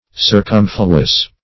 Circumfluous \Cir*cum"flu*ous\